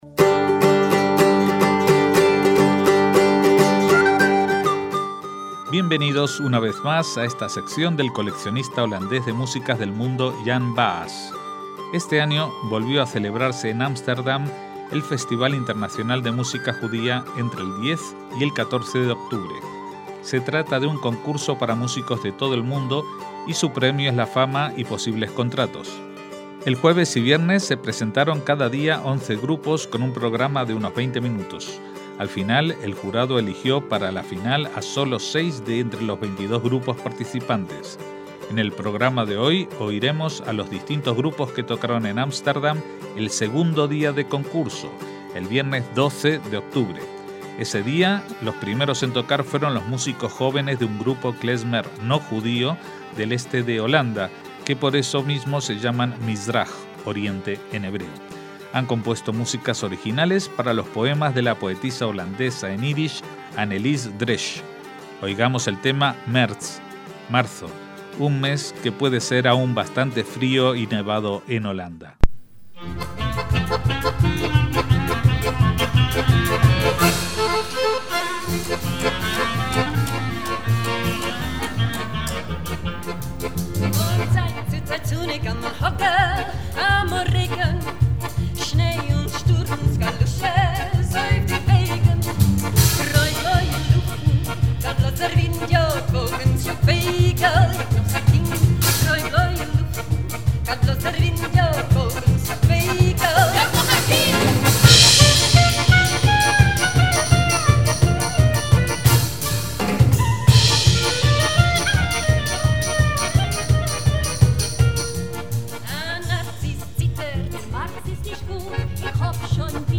Seguimos emitiendo los programas dedicados a las diferentes ediciones del Festival de Música Judía de Ámsterdam, en esta ocasión con el segundo día de la edición de 2012.